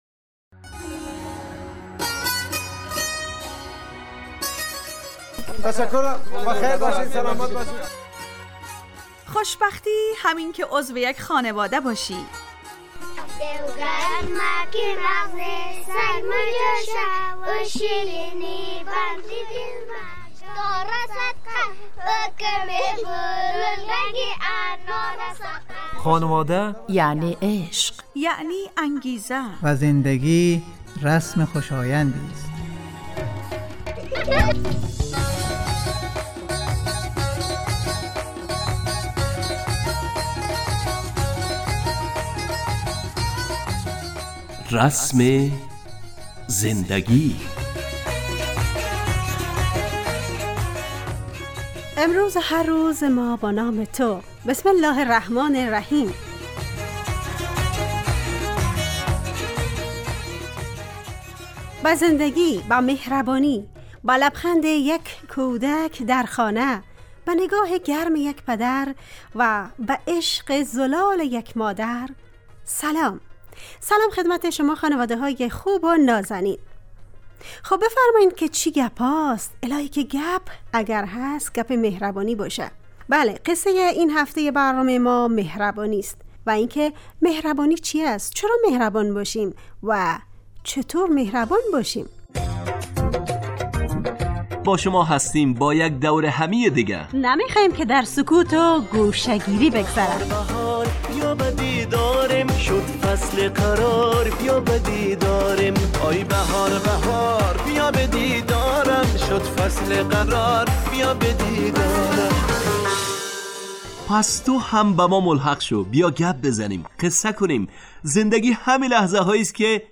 رسم زندگی_ برنامه خانواده رادیو دری ___شنبه 17 آبان 404 ___ مهربانی _ گوینده و تهیه کننده و میکس